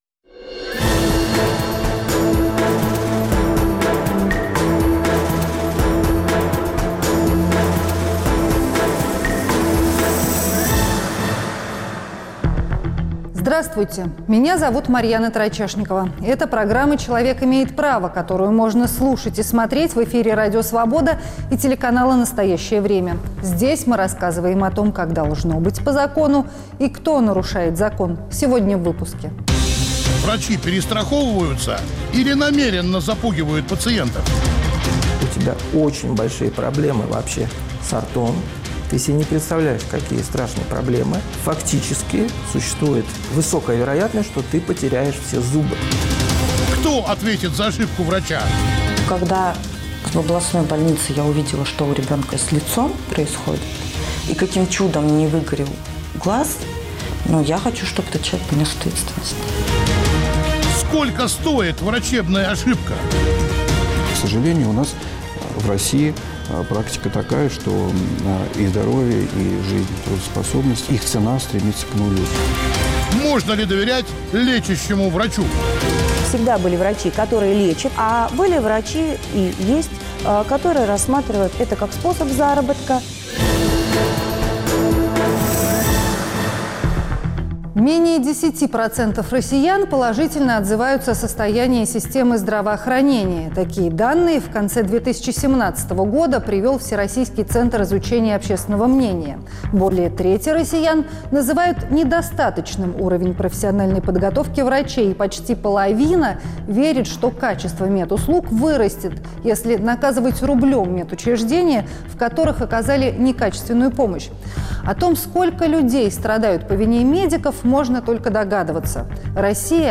Имеет ли врач право на ошибку, и как сохранить баланс в защите интересов пациентов и врачей? Спросим у экспертов в студии